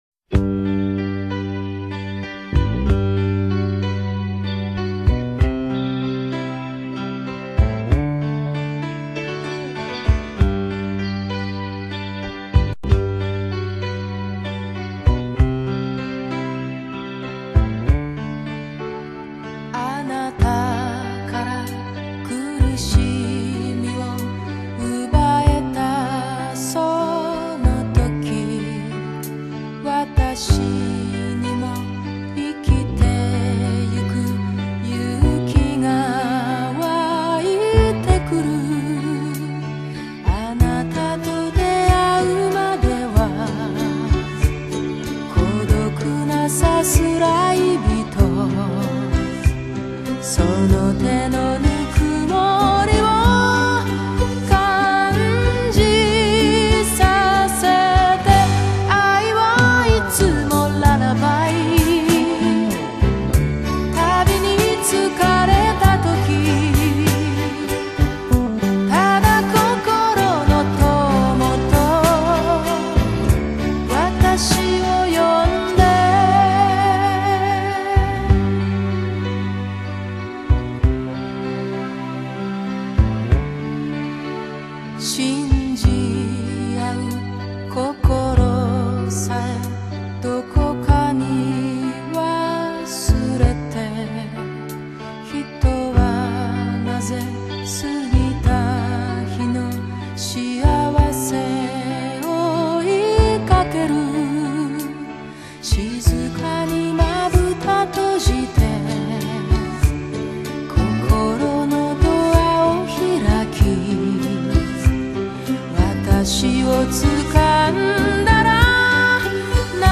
隽永婉约  情感细腻  淡定从容
清雅深邃  闪着智慧清辉的极致歌声